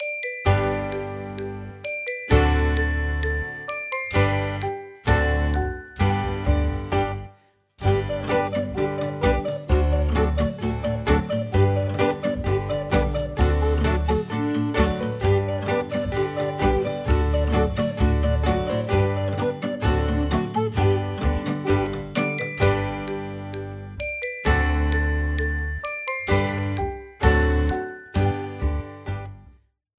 für eine oder zwei Sopranblockflöten
Besetzung: 1-2 Sopranblockflöten